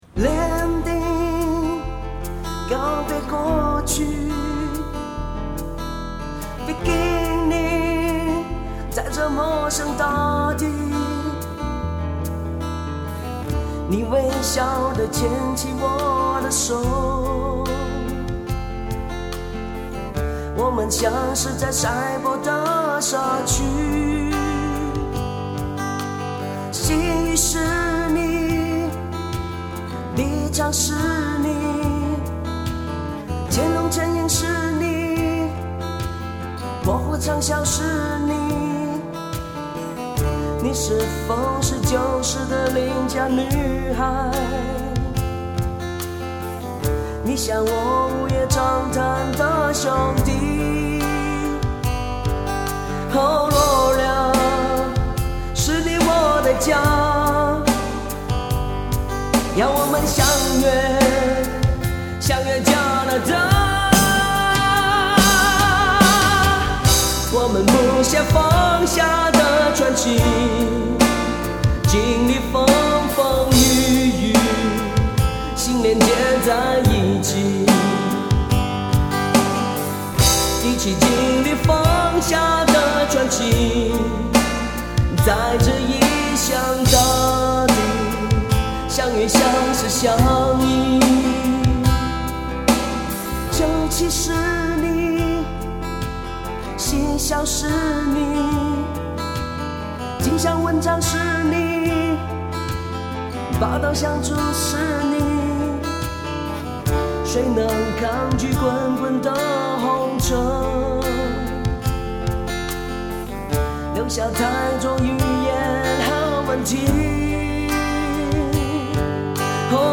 我觉得这个有点厂歌企业歌的味道。。。